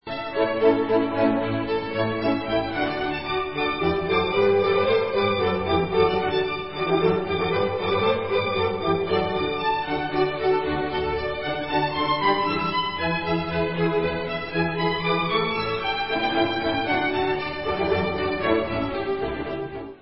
housle
Koncert pro housle a orchestr D dur:
Allegro